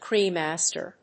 /kɻiˈmæstəɻ(米国英語), kɹiːˈmastə(英国英語)/